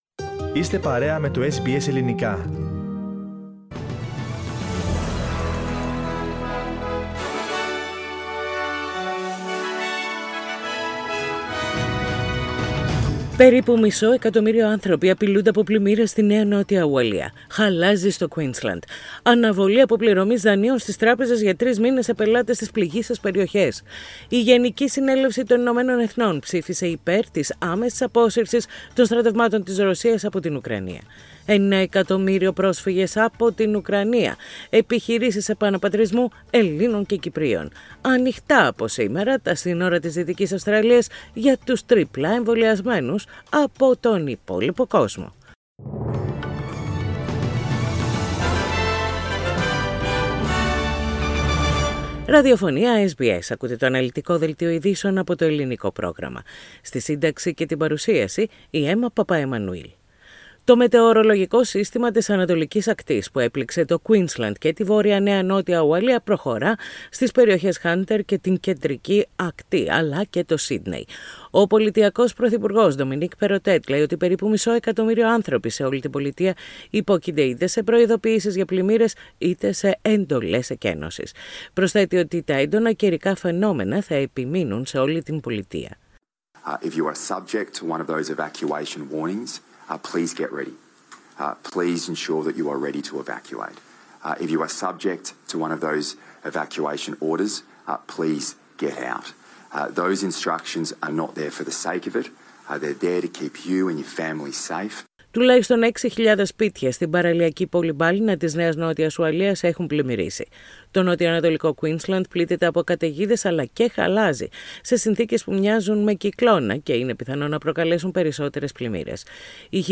Main news bulletin in Greek.